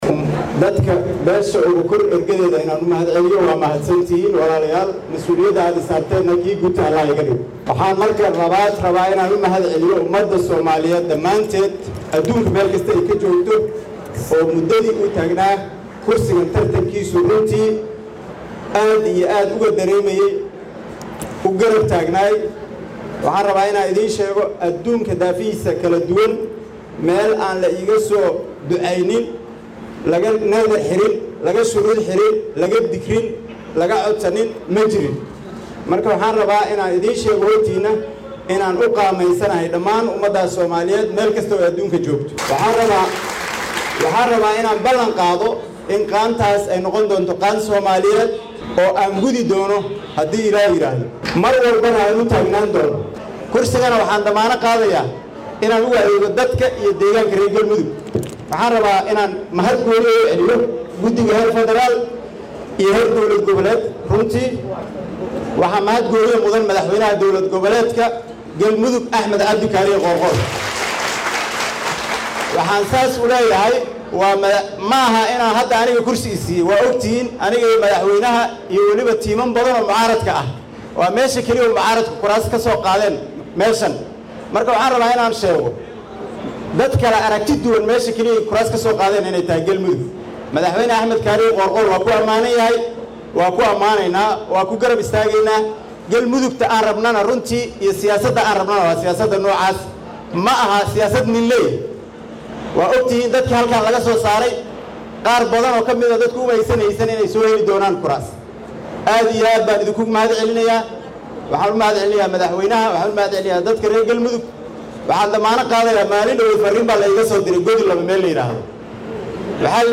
Xildhibaan Cabdiraxmaan Maxamad Xuseen Odowaa ayaa Guushiisa Ka dib Khudbad u jeediyay Ergadii Dooratay Iyo Martidii Ka Soo Qayb gashay Doorashadiisa.